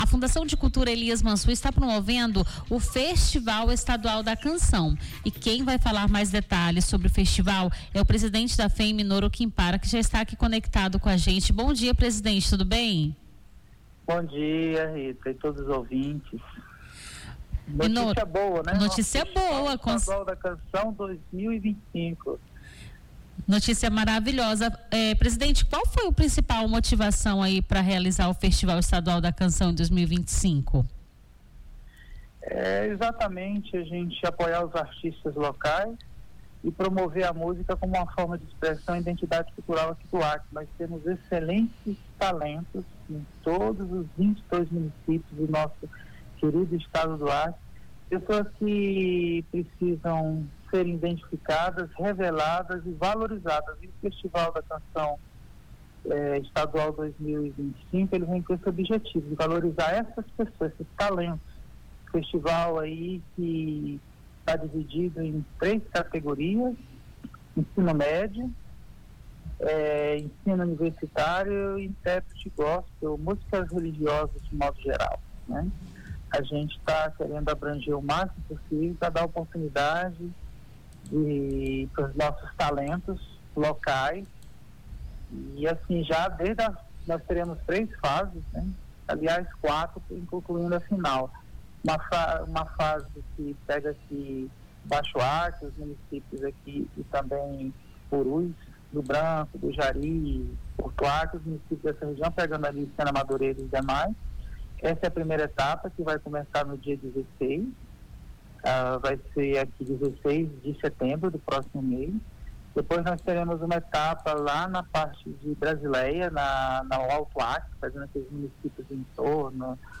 Nome do Artista - CENSURA - ENTREVISTA (FESTIVAL ESTADUAL DA CANÇÃO) 13-08-25.mp3